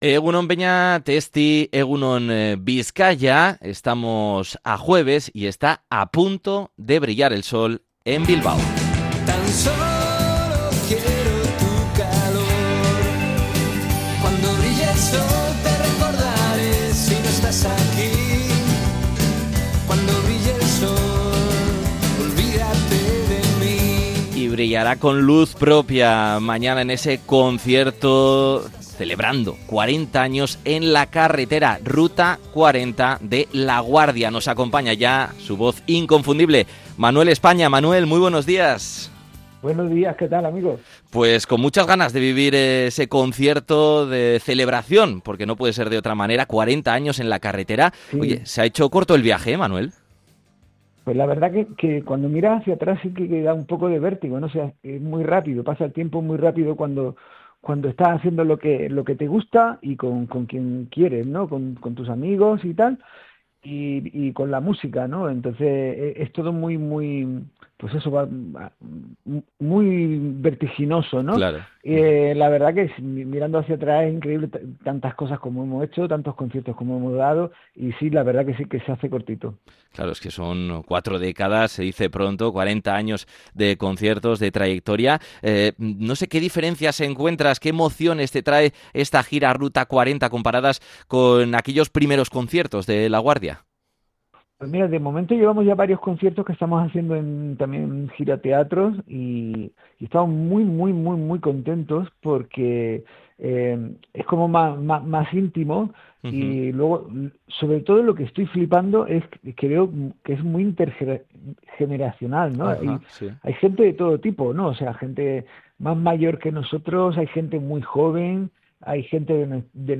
Entrevista a Manuel España, cantante de La Guardia